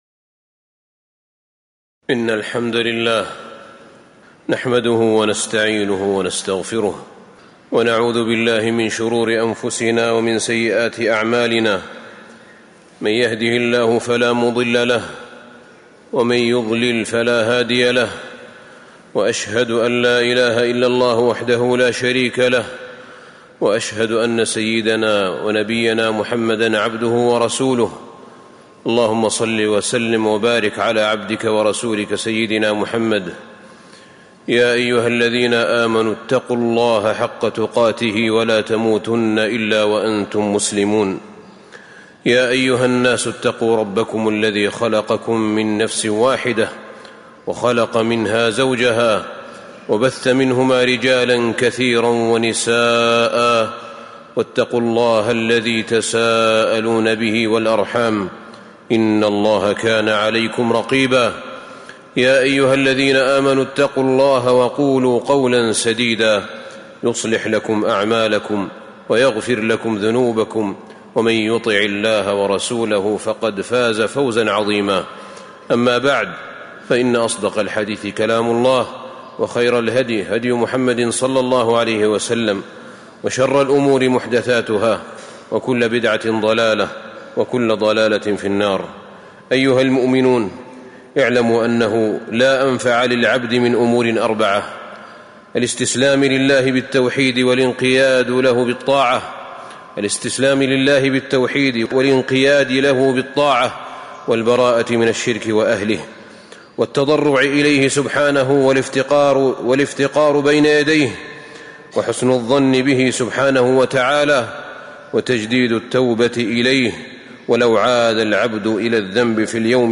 تاريخ النشر ١٣ شعبان ١٤٤٥ هـ المكان: المسجد النبوي الشيخ: فضيلة الشيخ أحمد بن طالب بن حميد فضيلة الشيخ أحمد بن طالب بن حميد من فضائل التضرع إلى الله تعالى